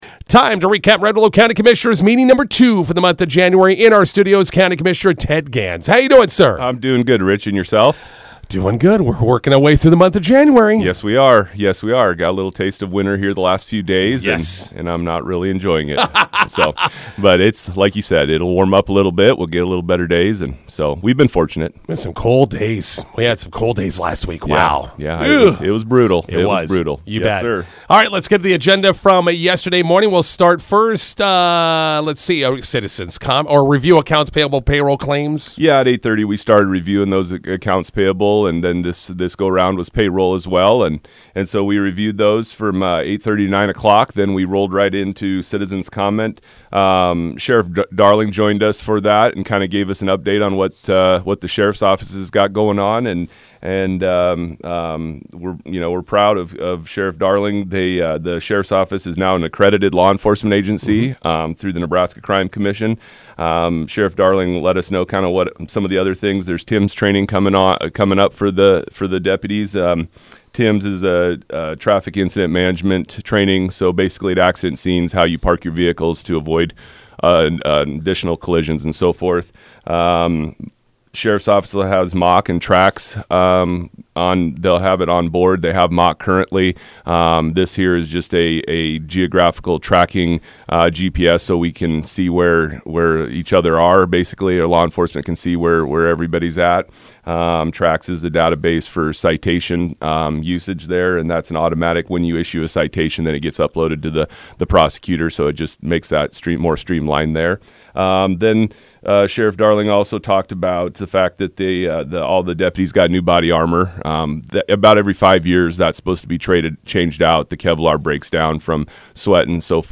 INTERVIEW: Red Willow County Commissioners meeting recap with County Commissioner Ted Gans. | High Plains Radio